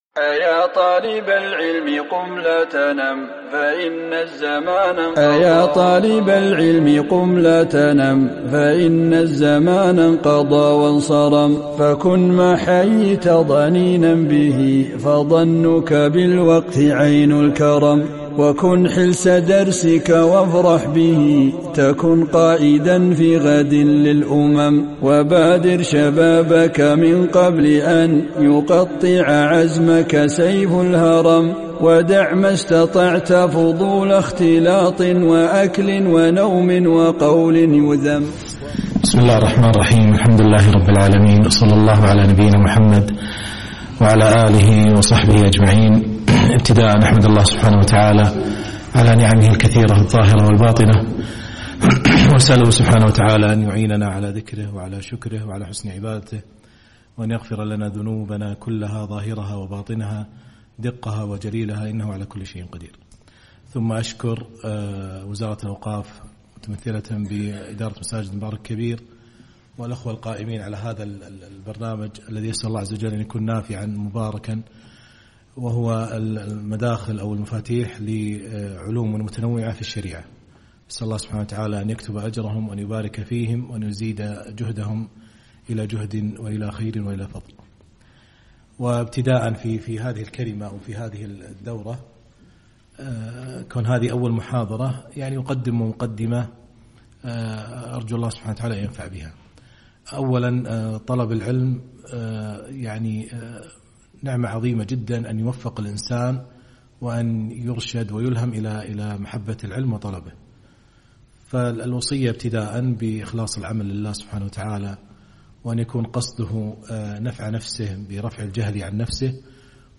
محاضرة بعنوان المدخل إلى علم العقيدة